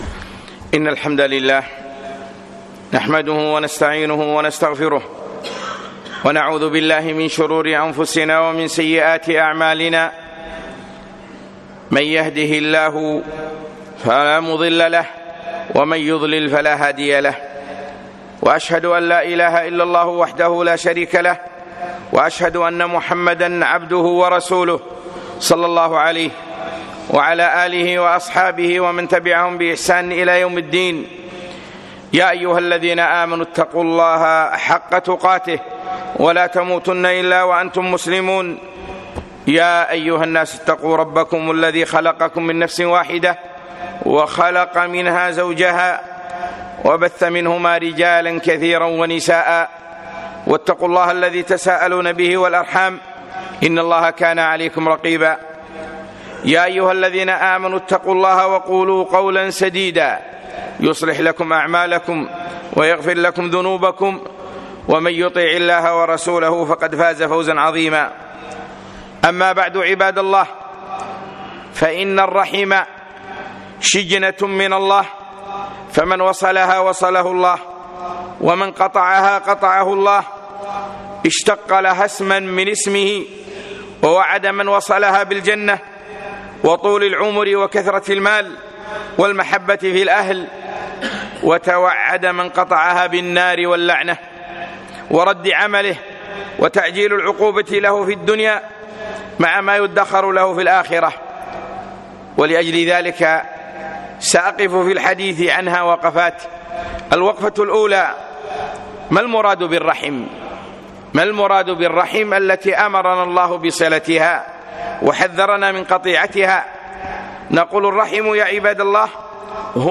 يوم الجمعة 9 3 2018 في مسجد الرحمن منطقة حولي